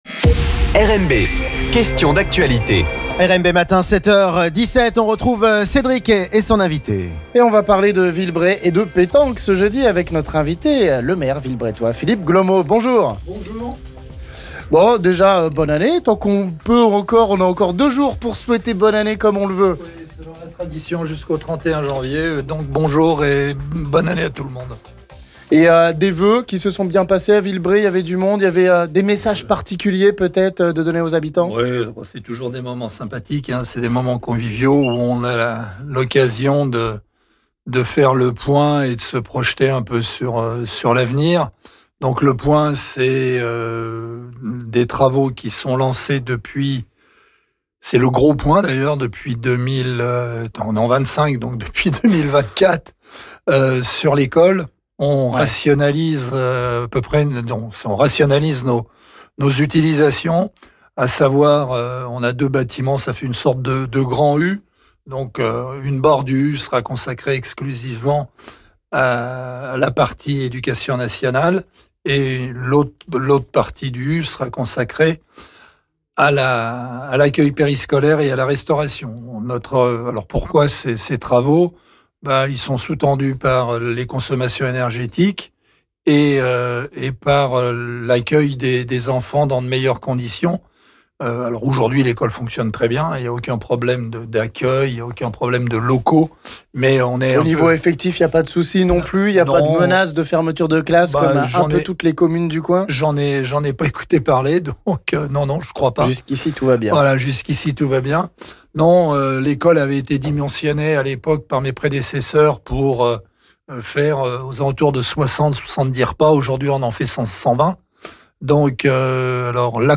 Le maire de Villebret Philippe Glomot nous parle de sa commune et de la P�tanque Dom�ratoise - L'Invit� du Jour